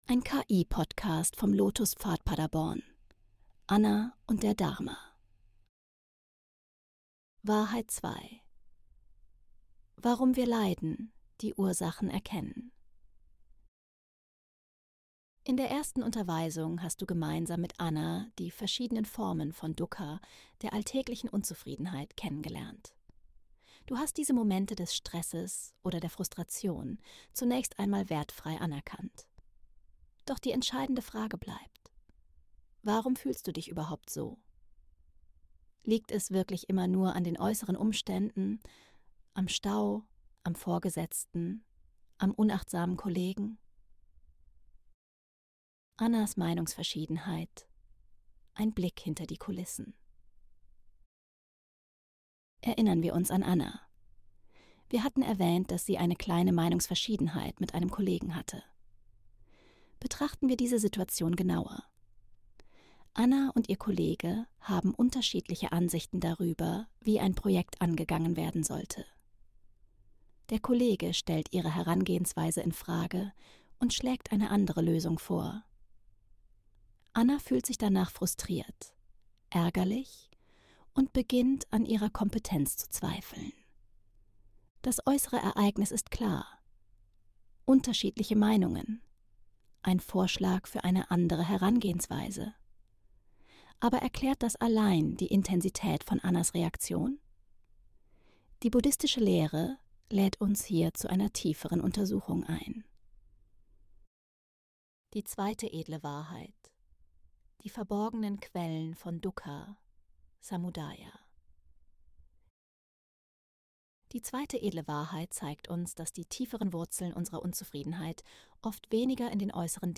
Diese Meditation und Achtsamkeit Unterweisung deckt die inneren